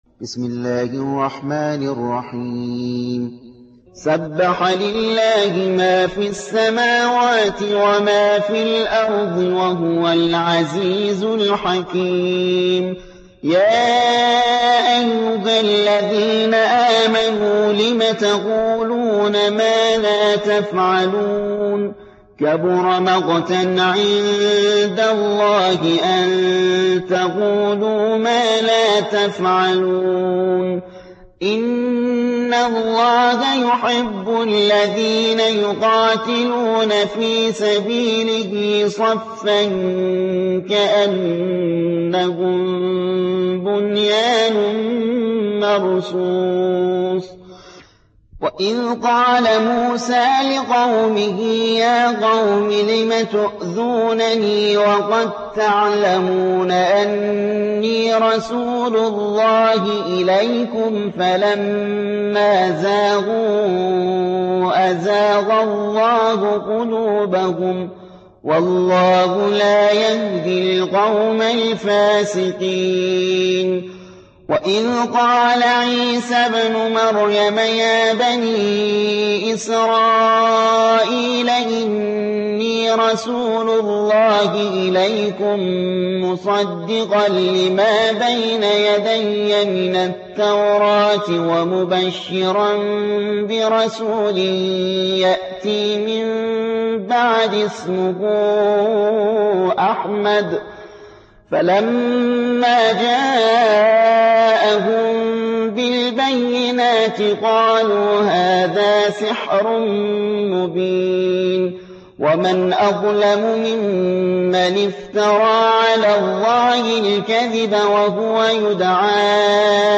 61. سورة الصف / القارئ